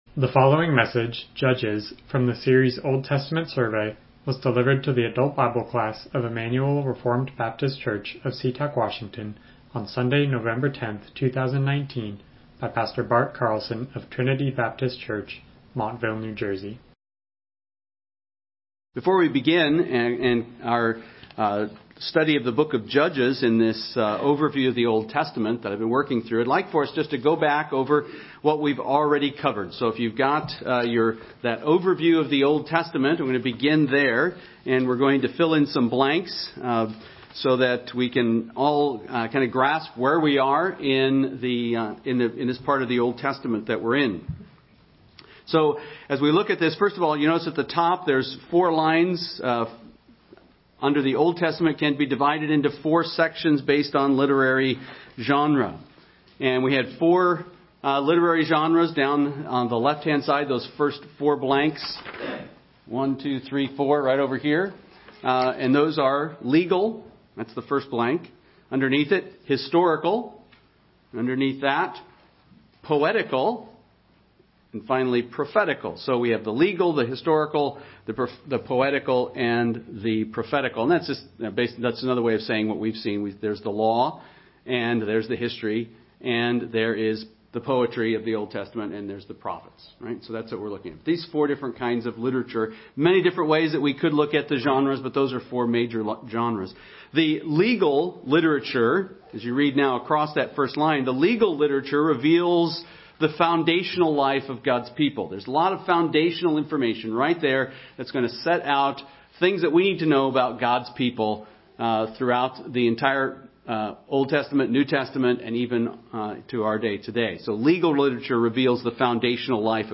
Old Testament Survey Service Type: Sunday School « The Text that Changed the World Holiness Keeps Things Together that Belong Together